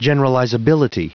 Prononciation du mot generalizability en anglais (fichier audio)
Prononciation du mot : generalizability